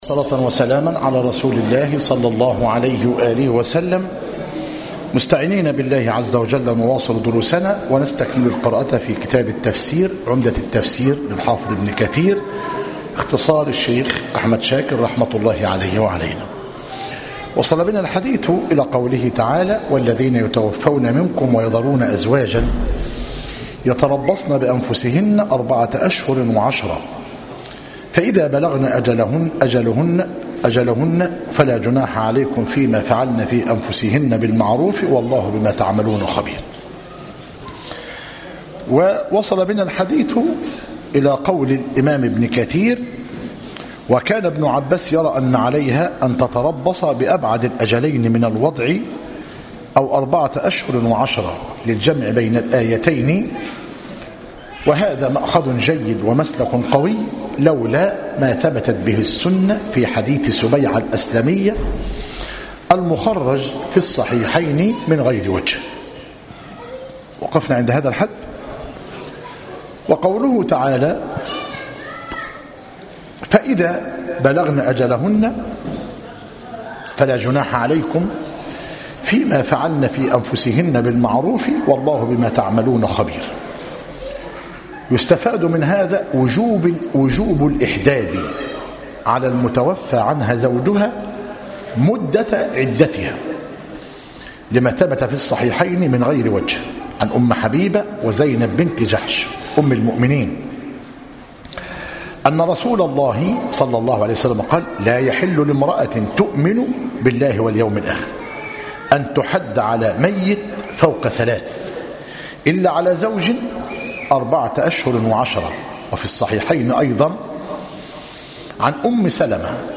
عمدة التفسير مختصر تفسير ابن كثير للعلامة أحمد شاكر رحمه الله - مسجد التوحيد - ميت الرخا - زفتى - غربية - المحاضرة الثالثة والثلاثون - بتاريخ 7 - ذو القعدة - 1436هـ الموافق 22- أغسطس- 2015 م